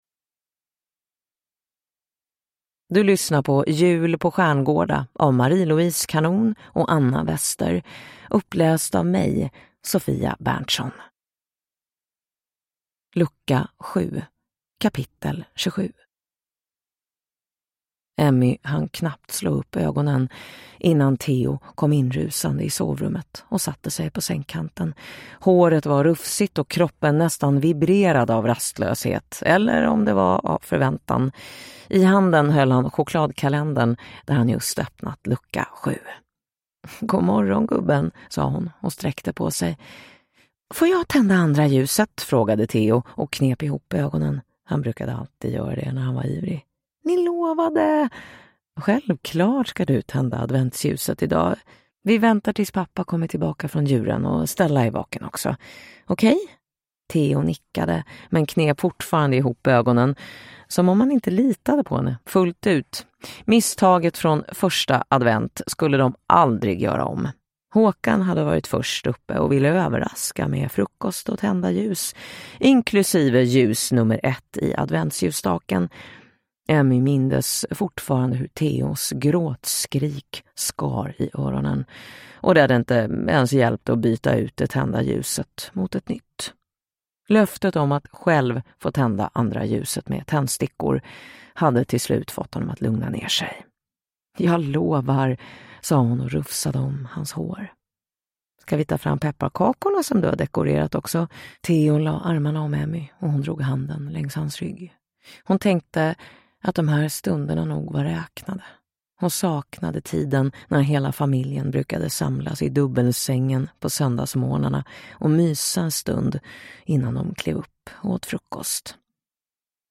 Jul på Stjärngårda: Lucka 7 – Ljudbok